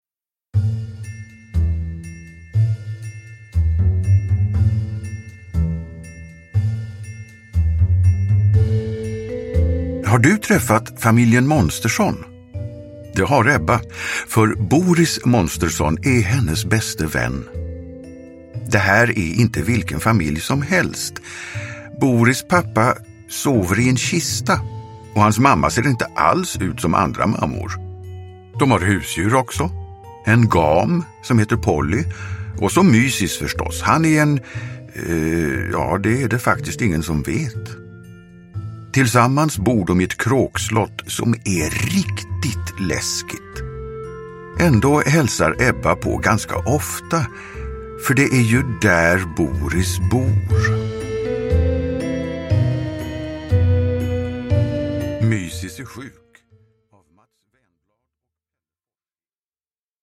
Mysis är sjuk – Ljudbok